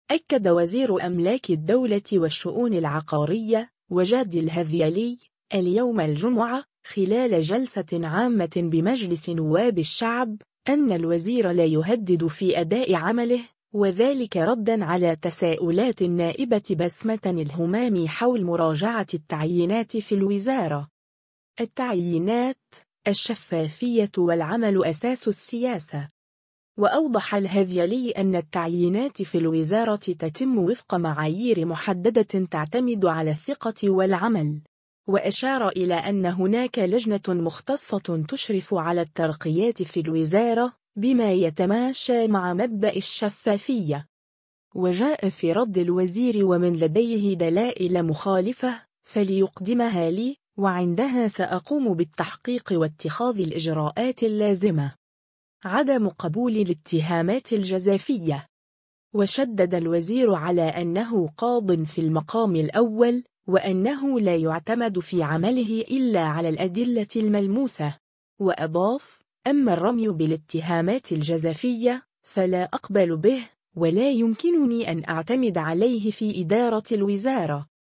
وزير أملاك الدولة خلال جلسة عامة بالبرلمان : الوزير لا يُهدد.. (فيديو)
أكد وزير أملاك الدولة والشؤون العقارية، وجدي الهذيلي، اليوم الجمعة، خلال جلسة عامة بمجلس نواب الشعب، أن الوزير لا يُهدد في أداء عمله، وذلك ردًا على تساؤلات النائبة بسمة الهمامي حول مراجعة التعيينات في الوزارة.